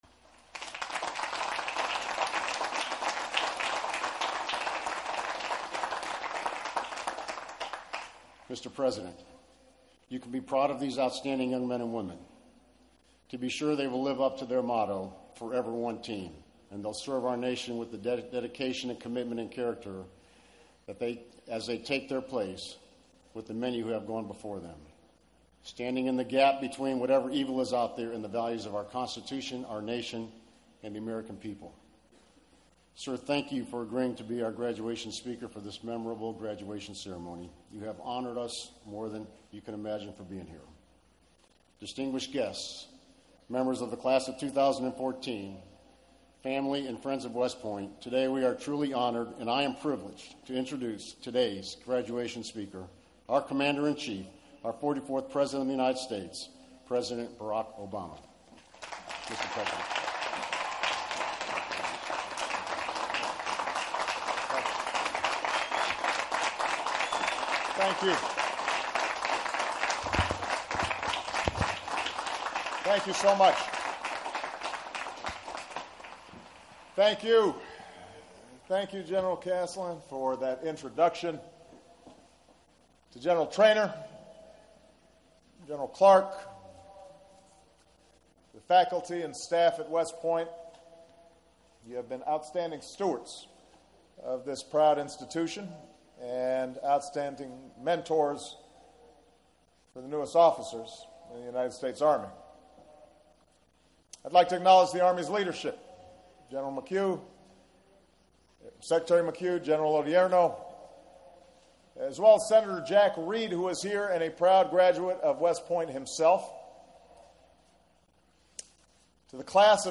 Barak Obama, commencement speech at West Point, 05/28/14